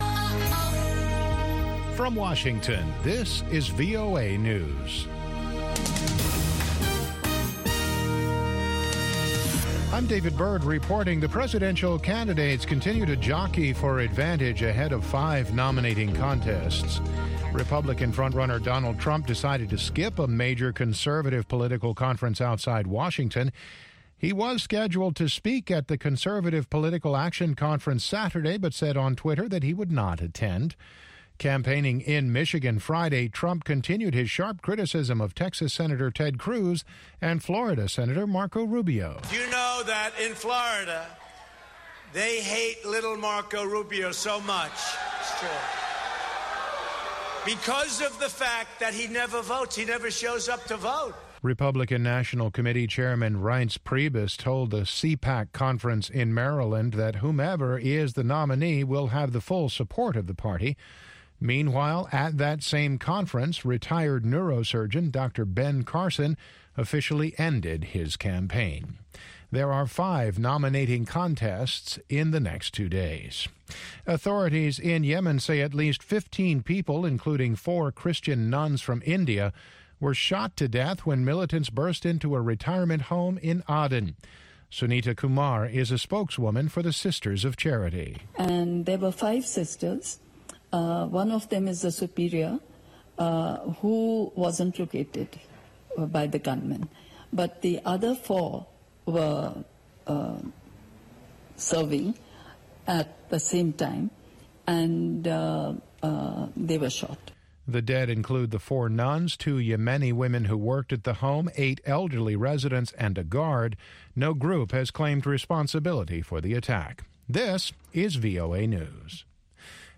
N’dombolo
Benga
African Hip Hop
the best mix of pan-African music